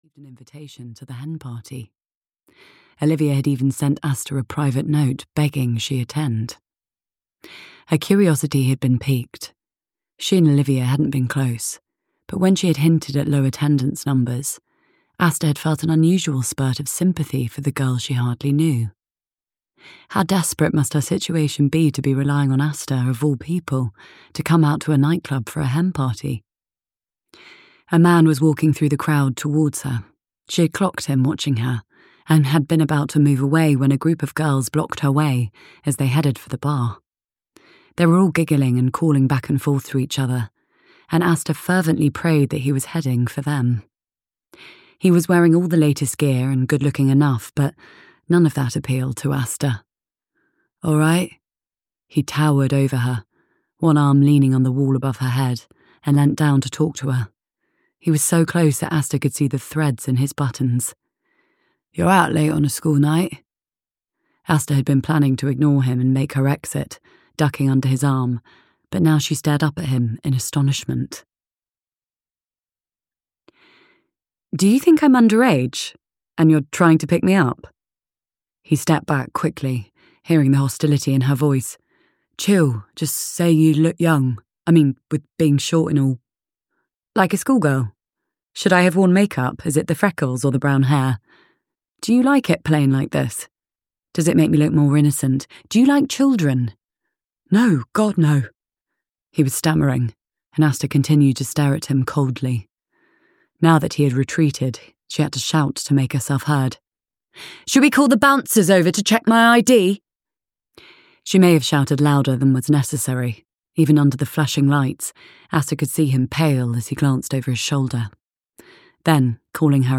Aster Victorious (EN) audiokniha
Ukázka z knihy